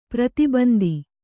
♪ prati bandi